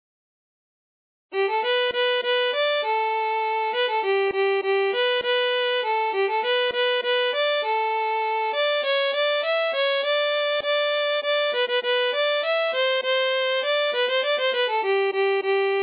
Envoyer un formulaire de recherche Le soir des noces de P.J. de B�ranger Compositeur : Paroles et musique de Beranger Chant grivois FAILED (the browser should render some flash content here, not this text).